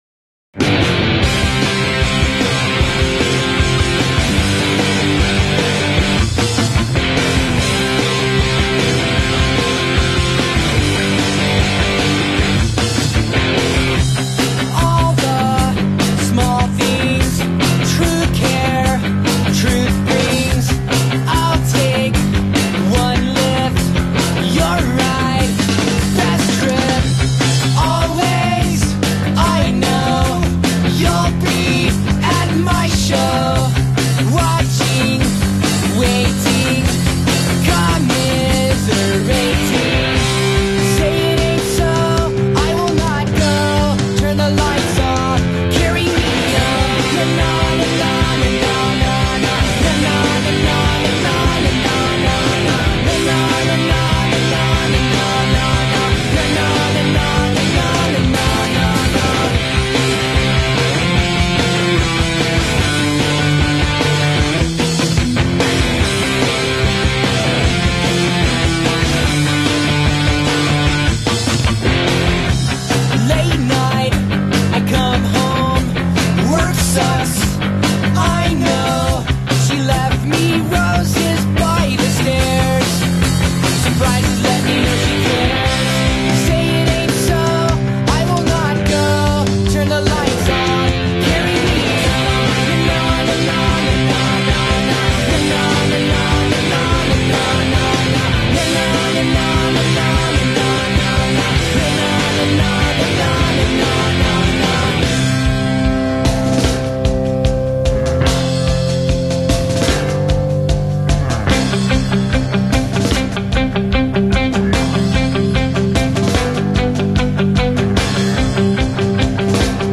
90s Pop-Punk outfit
In session at The BBC